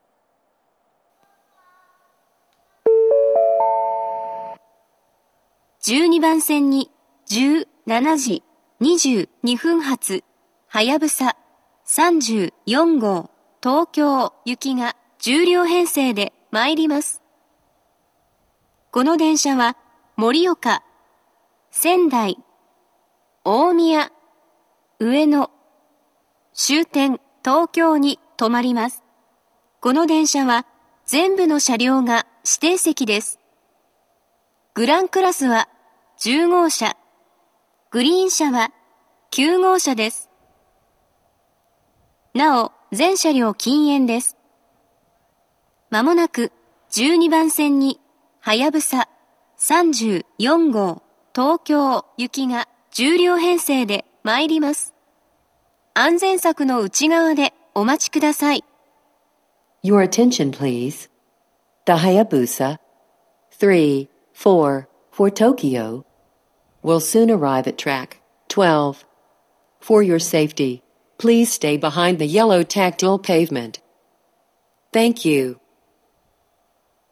１２番線接近放送
接近放送は及び到着放送は「はやぶさ３４号　東京行」です。